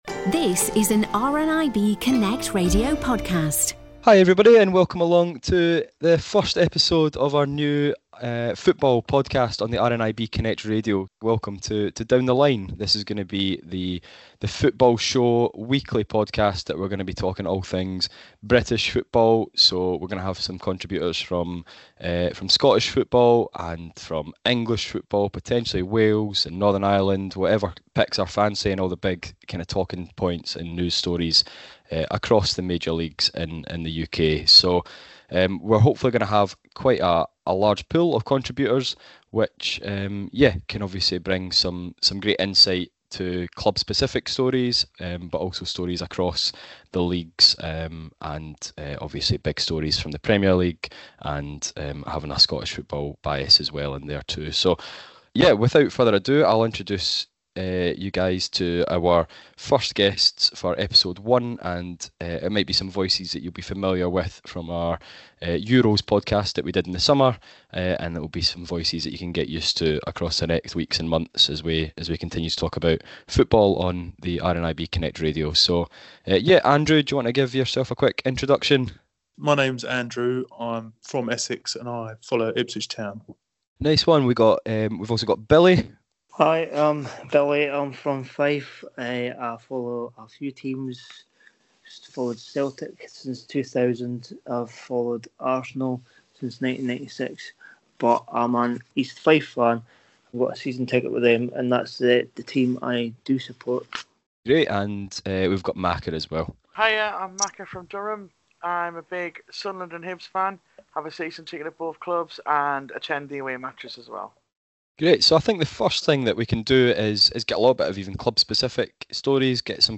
As the football season gets underway in Scotland, members of RNIB Community Connections Telephone groups have got together to share their thoughts on the upcoming action this weekend.